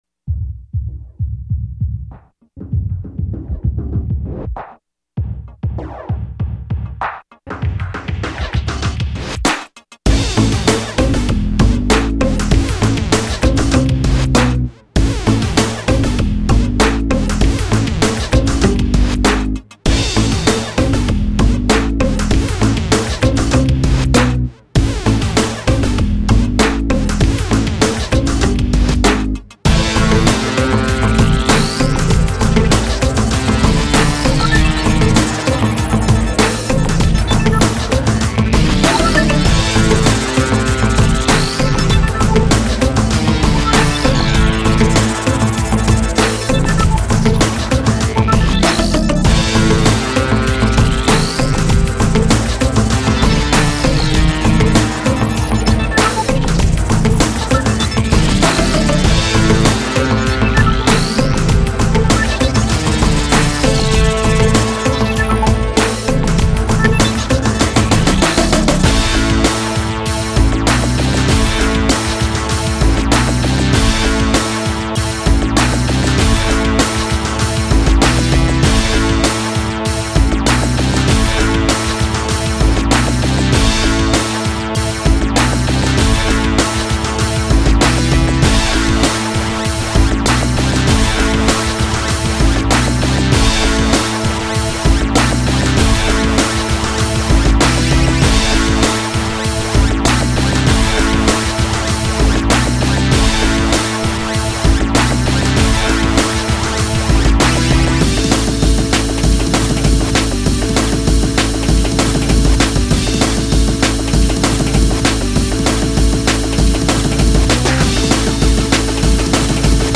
사운드데모(하드코어